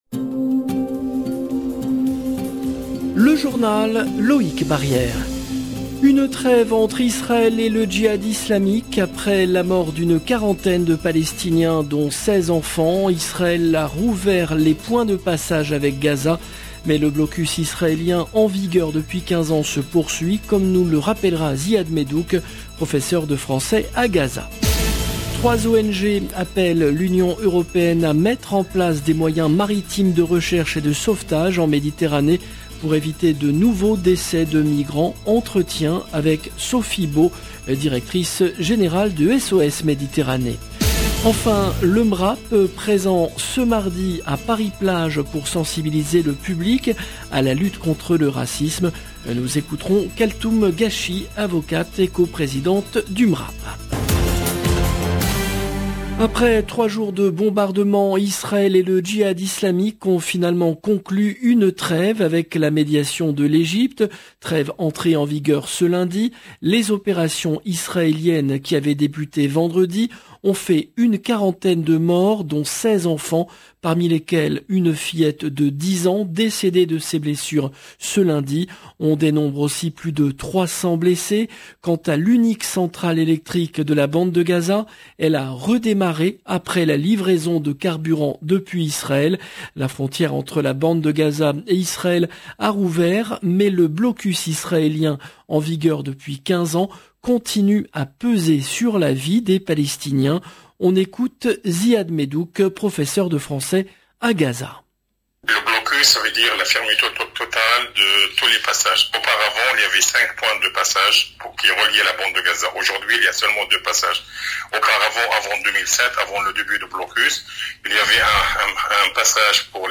Gaza MRAP SOS MEDITERRANEE 8 août 2022 - 17 min 46 sec LE JOURNAL DU SOIR EN LANGUE FRANCAISE DU 8/08/22 LB JOURNAL EN LANGUE FRANÇAISE Une trêve entre Israël et le Jihad islamique.